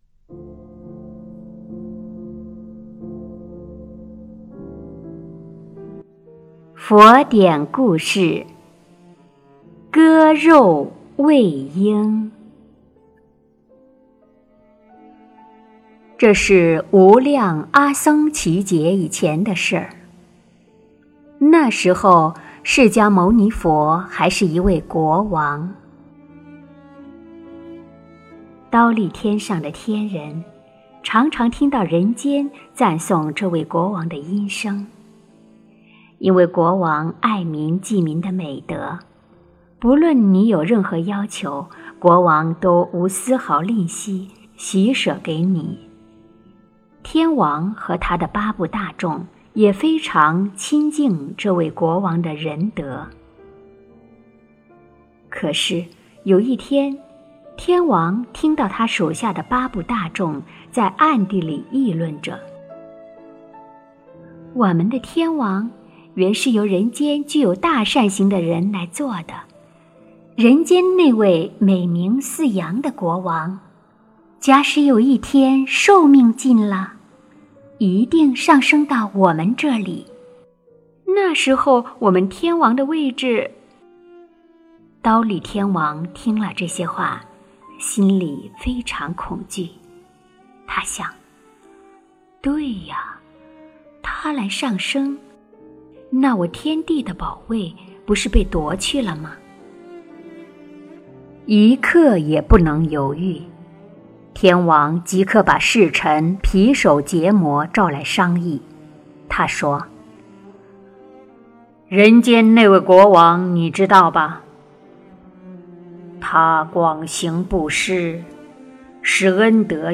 佛音 诵经 佛教音乐 返回列表 上一篇： 给孤独夫妇 下一篇： 舍身饲虎 相关文章 透过你温柔的气息Through your Tender Breath--瑜伽静心曲 透过你温柔的气息Through your Tender Breath--瑜伽静心曲...